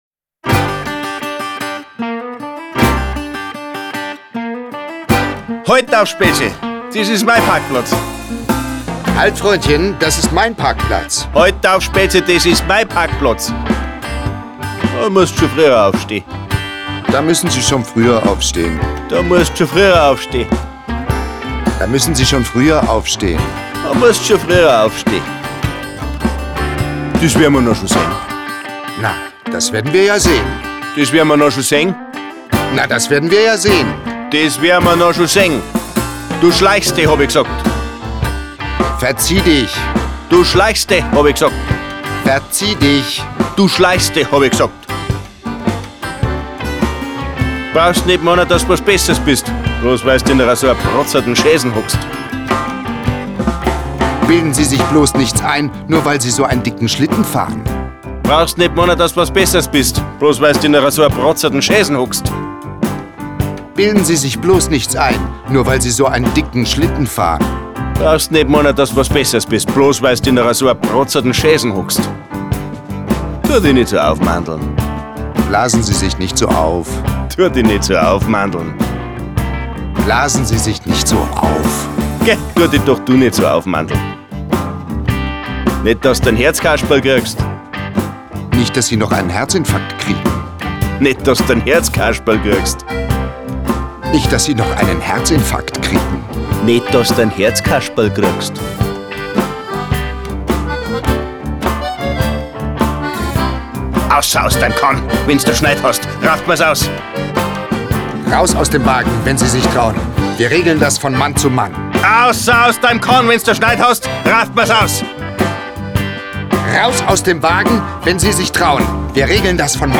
Local Grooves mit Udo Wachtveitl.Coole Pop & Jazz Grooves / Audio-CD mit Booklet
Mit zünftig-groovigen Rhythmen und witzigen Dialogen sorgen die Local Grooves für Stimmung. Der Münchner Tatort-Kommissar Udo Wachtveitl enthüllt die Geheimnisse des bayerischen Dialekts und hilft bei der Aussprache.
Bayrisch mit Udo Wachtveitl - bayerischer Dialekt verknüpft mit coolen Pop & Jazz Grooves.